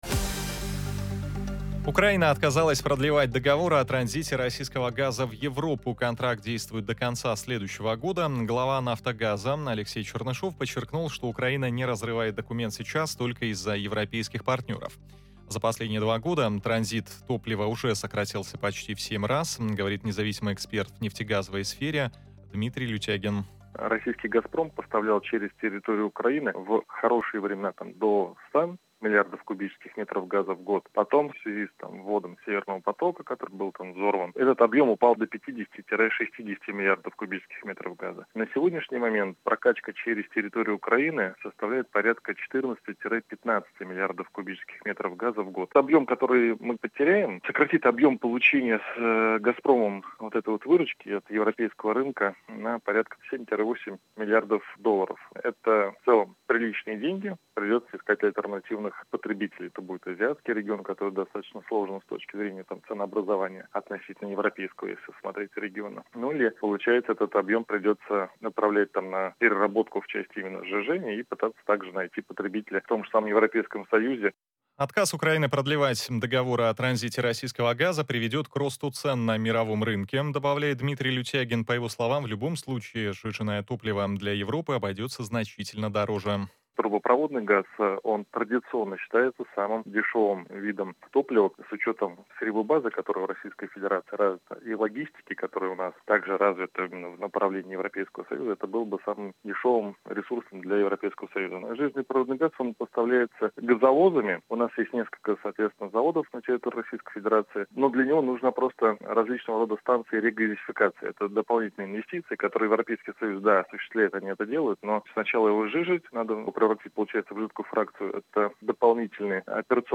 независимый эксперт в нефтегазовой сфере:
Источник: Москва FM, 30.10.2023